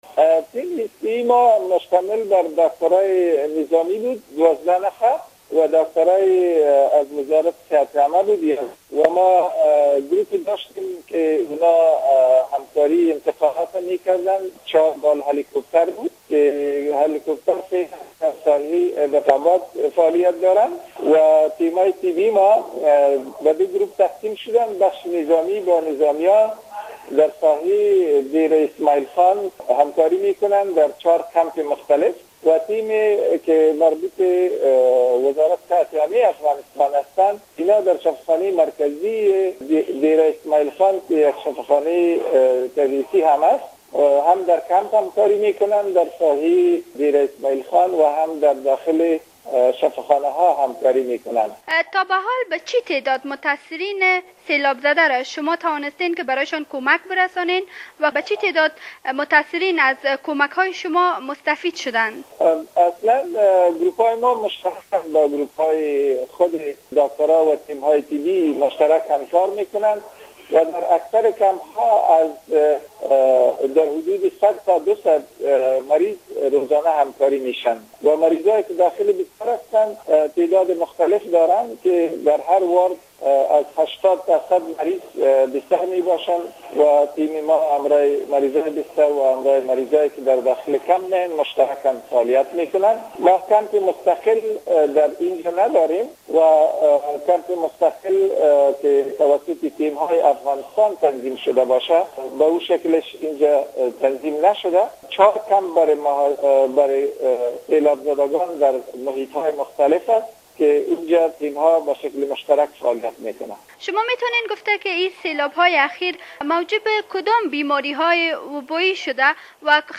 مصاحبه با یک مسوول تیم اعزامی مساعدت افغانستان به قربانیان سیلاب های اخیر در پاکستان